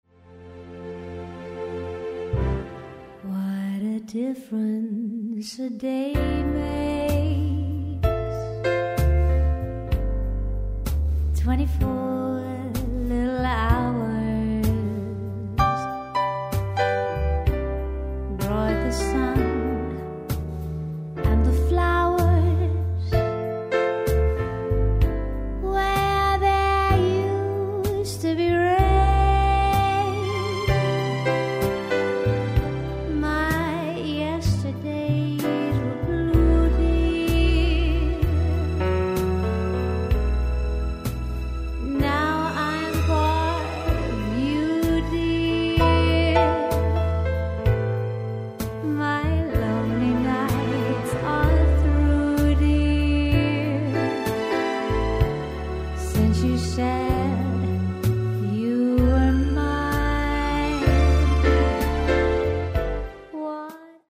• Solo Singer